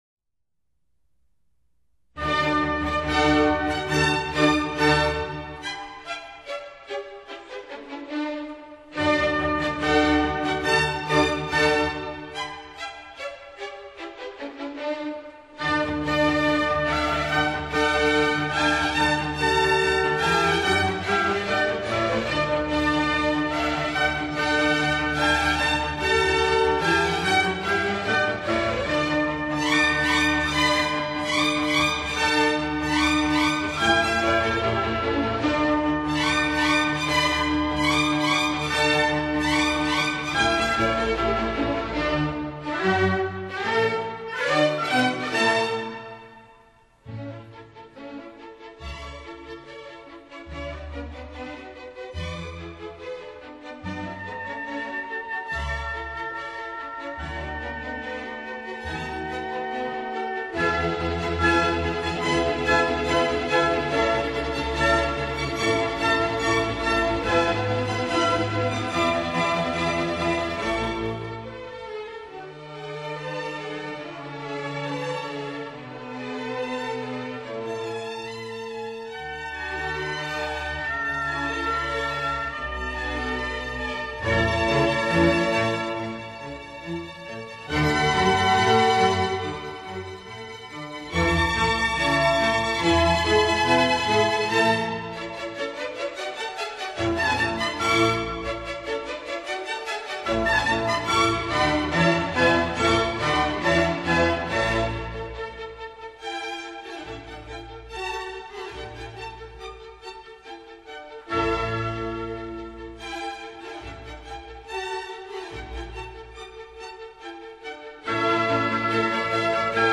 虽然听上去有些过于厚重，但却十分大气